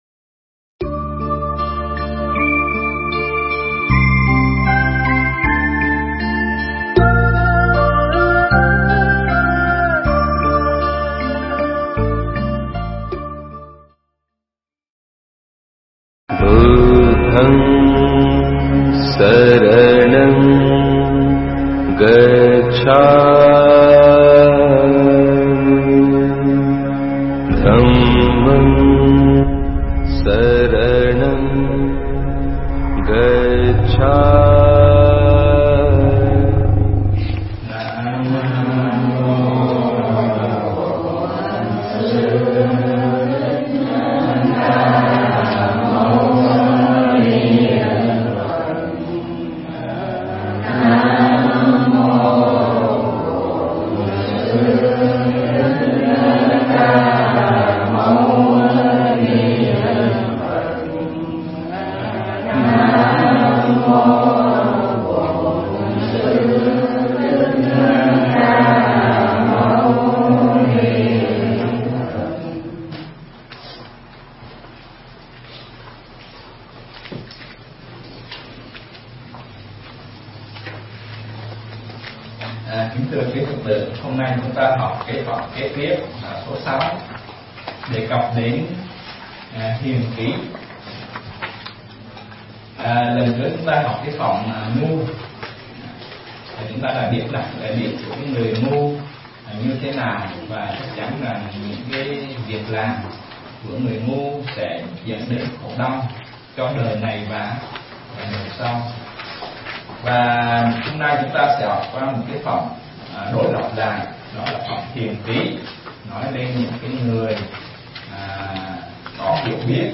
Mp3 Thuyết pháp Kinh Pháp Cú Phẩm Hiền Trí